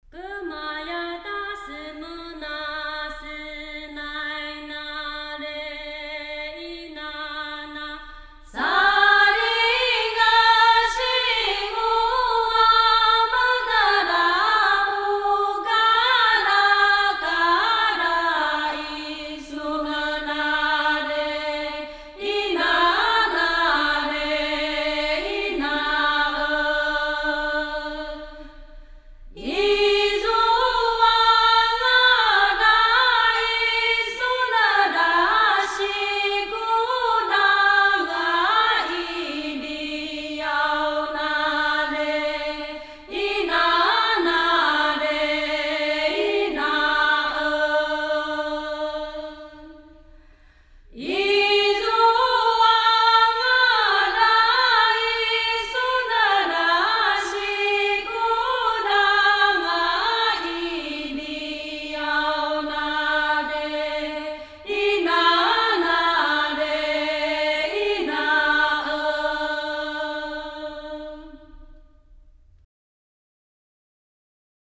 ◎音樂類別： 台灣原住民傳統歌謠
鄒族、阿美族、卑南族年祭樂舞